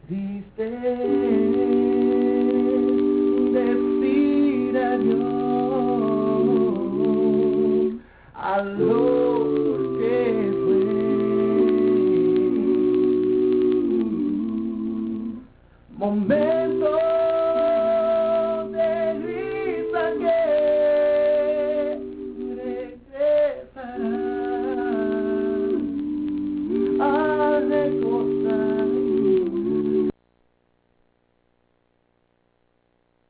Live Clips